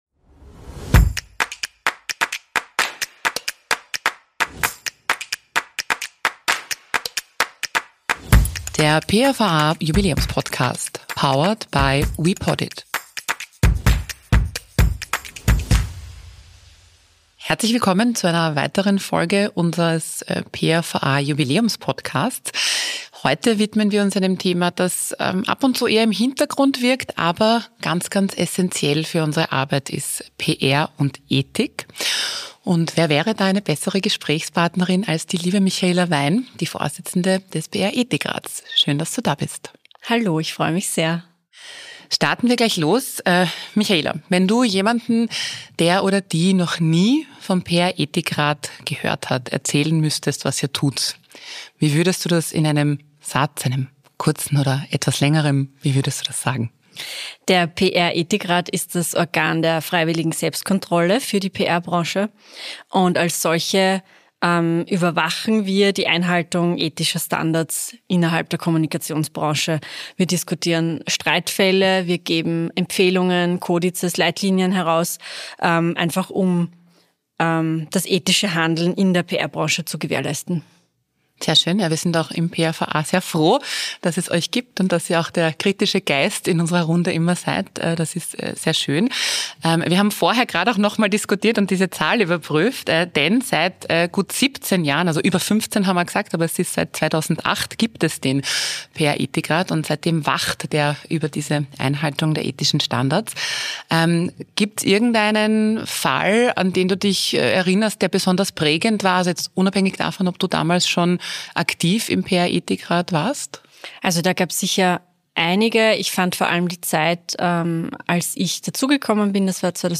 Ein Gespräch über Grundwerte, Grauzonen und Grenzen – vom Influencer:innen-Check bis zu den Herausforderungen generativer KI.